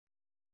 ♪ pōł